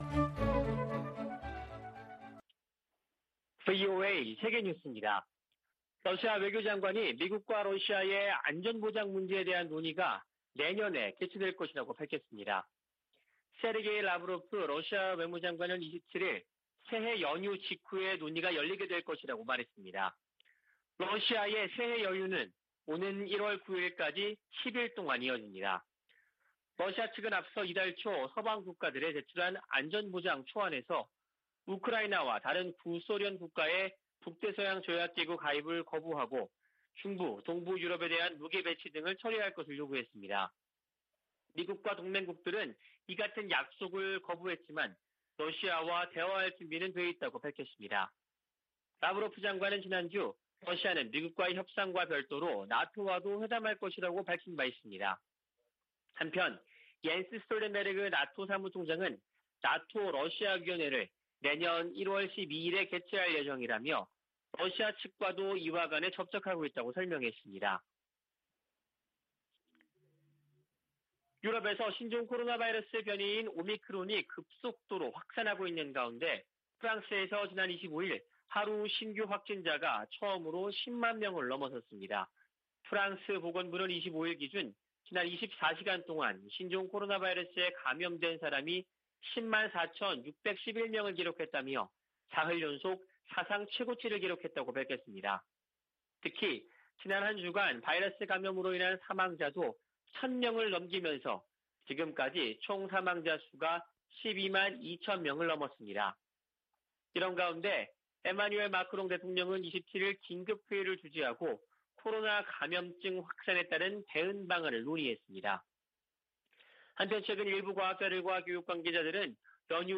VOA 한국어 아침 뉴스 프로그램 '워싱턴 뉴스 광장' 2021년 12월 28일 방송입니다. 2022년 새해를 앞두고 조 바이든 미국 행정부의 대북 전략에 대한 전문가들의 전망과 제언이 이어지고 있습니다. 로버트 에이브럼스 전 주한 미군사령관은 미국과 한국이 연합훈련 일부 재개를 진지하게 논의할 시점이라고 말했습니다. 북한이 일부 경제 부문의 성과를 부각하며 올 한 해를 승리의 해라고 연일 선전하고 있습니다.